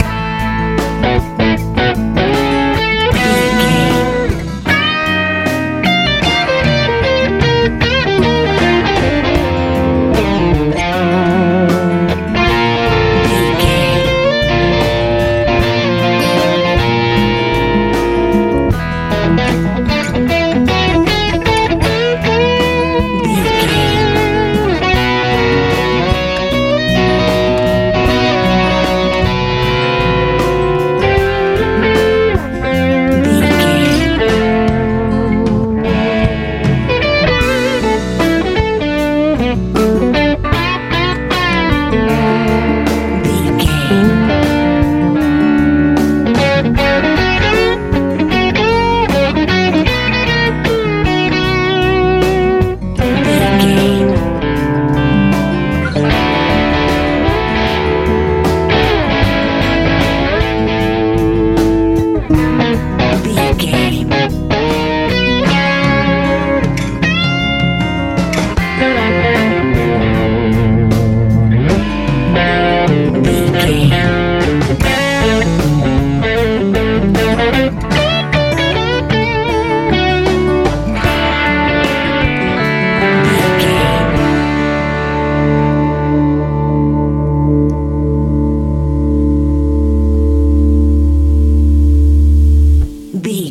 Ionian/Major
cool
hopeful
magical
drums
bass guitar
electric guitar
acoustic guitar
driving
happy
inspirational